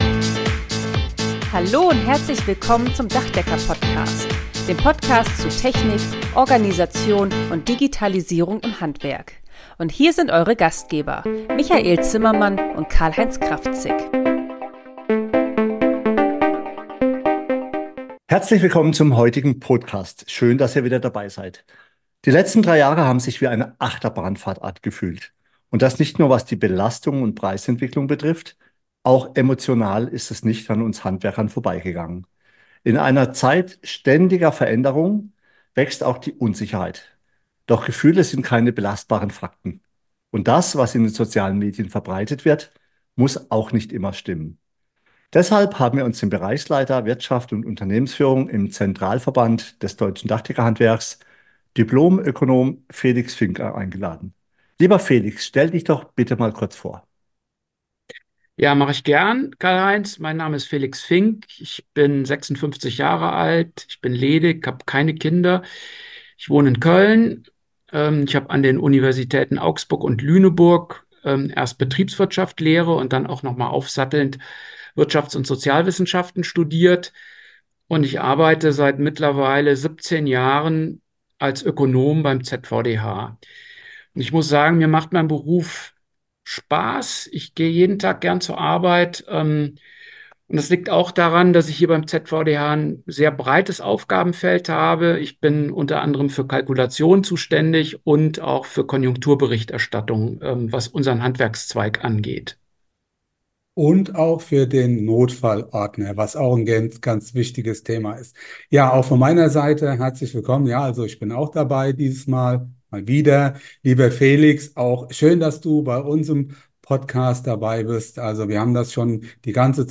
Interview mit Dipl.